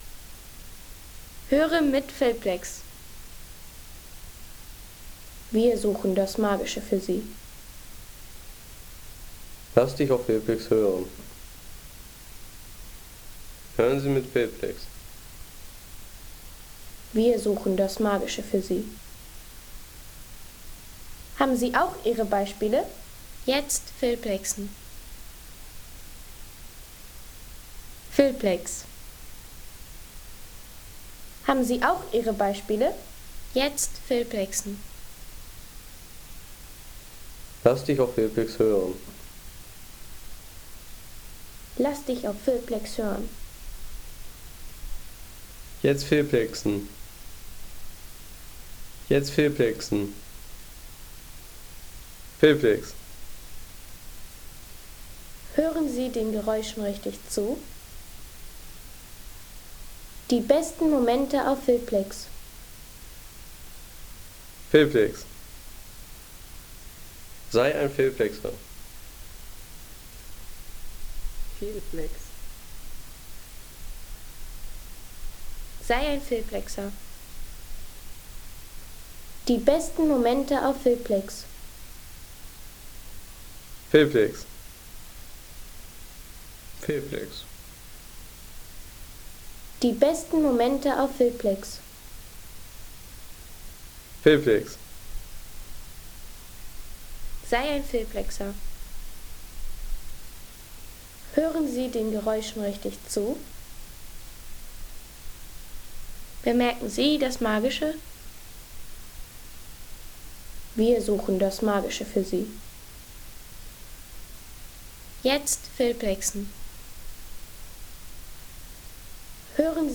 Das Rauschen des Waldes: Wind & Kranichrufe | Feelplex
Wind in den Baumkronen, sanftes Blätterrauschen und ferne Kranichrufe über grüner Landschaft in Schweden.
Baumkronen in Bewegung, Blätterrauschen und ferne Kranichrufe. Ruhige schwedische Waldatmosphäre für Film, Podcast und Klangpostkarten.